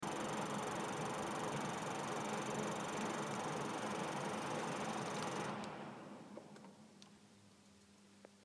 Bruit ventilation sur clim HITACHI raf-32cnh1
Depuis quelques jours, un bruit est apparu sur mon climatiseur réversible Hitachi raf-32cnh1 sur la partie intérieure (voir pièce jointe).
Avant, c'était quelques minutes et je n'y faisais pas attention, mais maintenant ce bruit est présent en permanence et semble accélérer ou ralentir en fonction de la puissance.
Bruit ventilation clim Hitachi.mp3